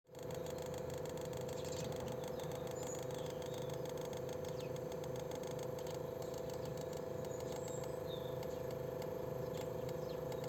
Voici le son en mode chaud.
Dans les deux cas, chaud et froid, j'entends plus un bruit de claquement moins normale que le premier enregistrement.
son-mode-chaud.mp3